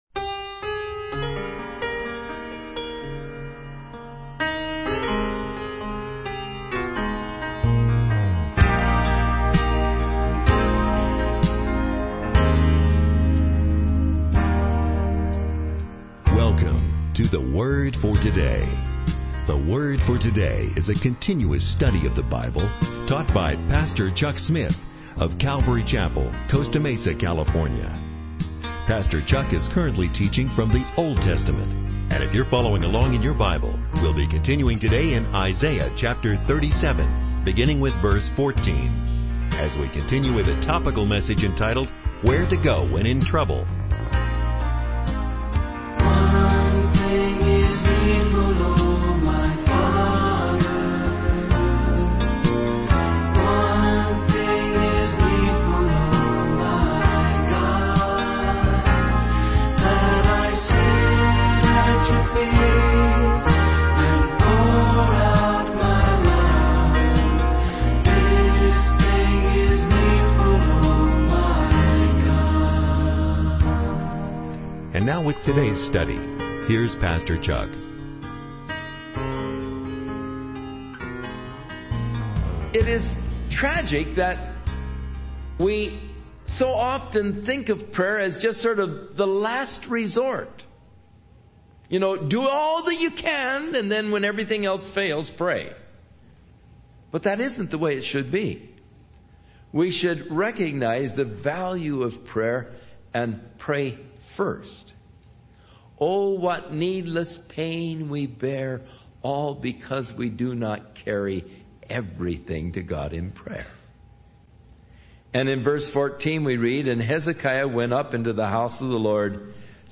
In this sermon, the speaker discusses the story of the descendants of Jacob and their journey out of Egypt. He highlights the encounter between Balaam and King Balak, where Balaam is initially forbidden by God to curse the invading people.